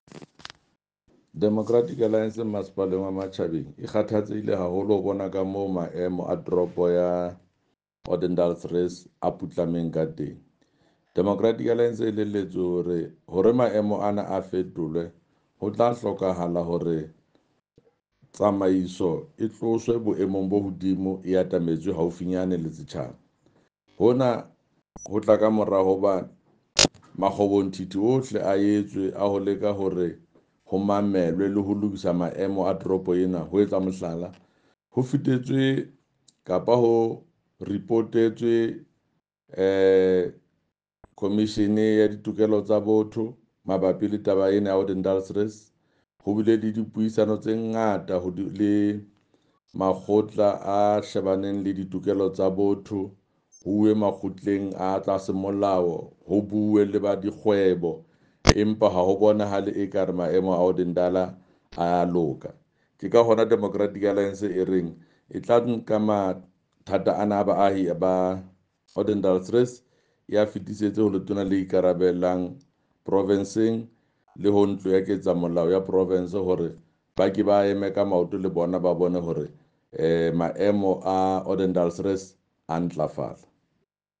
Sesotho by Jafta Mokoena MPL.